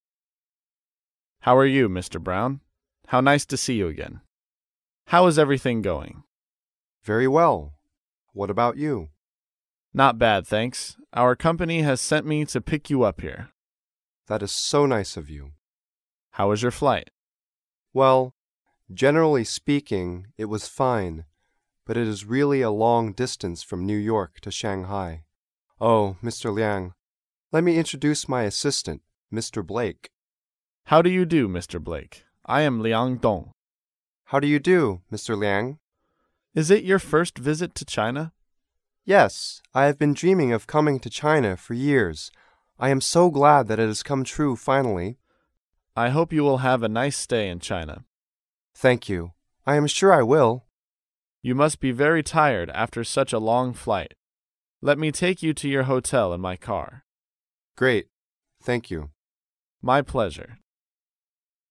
在线英语听力室高频英语口语对话 第33期:代公司接机的听力文件下载,《高频英语口语对话》栏目包含了日常生活中经常使用的英语情景对话，是学习英语口语，能够帮助英语爱好者在听英语对话的过程中，积累英语口语习语知识，提高英语听说水平，并通过栏目中的中英文字幕和音频MP3文件，提高英语语感。